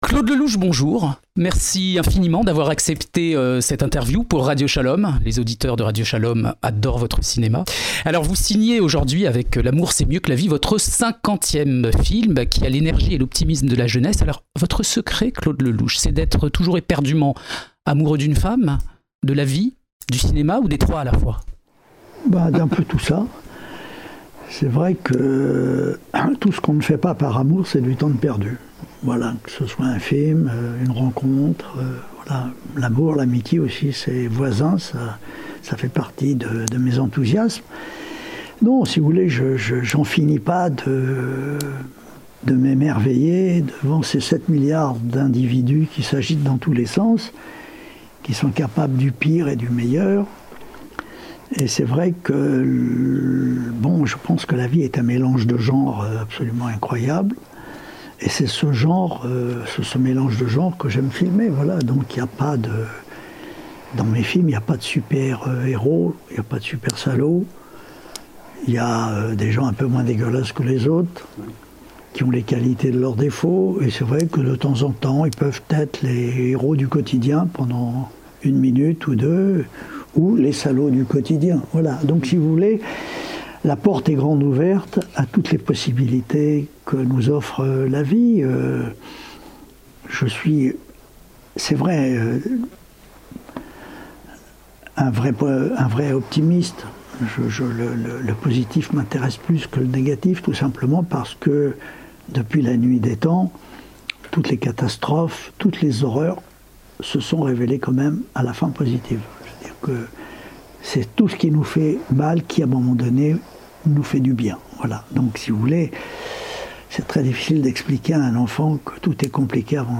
Avec générosité et enthousiasme, le célèbre cinéaste a reçu Radio Shalom et livré ses réflexions sur la vie, l'amour, la mort, le hasard, Dieu, l'échec et l'espoir...